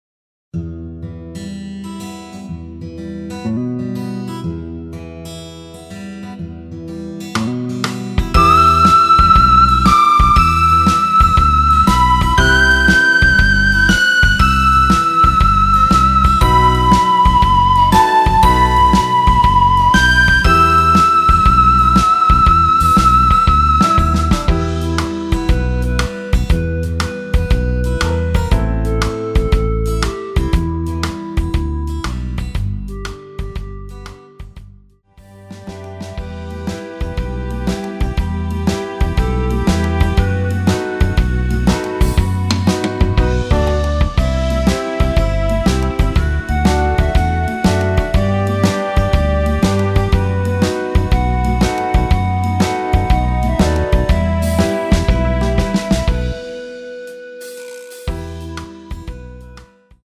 엔딩이 페이드 아웃으로 끝나서 라이브에 사용하실수 있게 엔딩을 만들어 놓았습니다.
원키 멜로디 포함된 MR입니다.
Em
앞부분30초, 뒷부분30초씩 편집해서 올려 드리고 있습니다.
(멜로디 MR)은 가이드 멜로디가 포함된 MR 입니다.